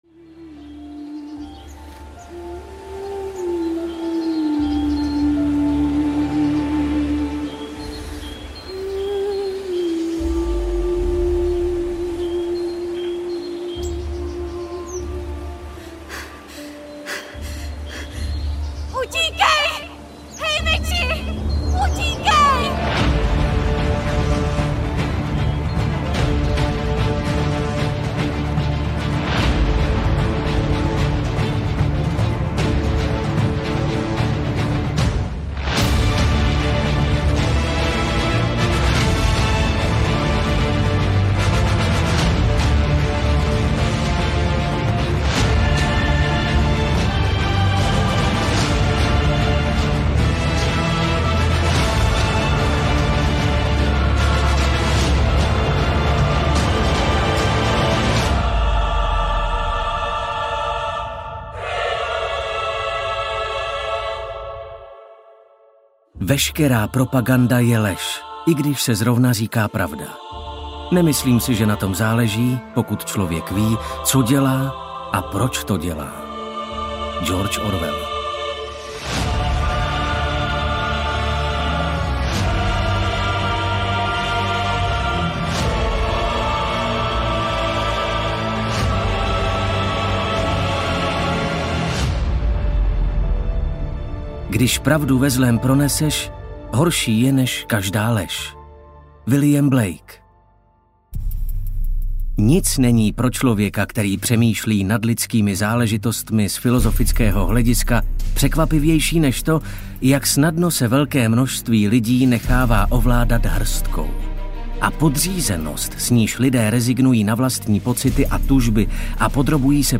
Úsvit sklizně audiokniha
Ukázka z knihy
usvit-sklizne-audiokniha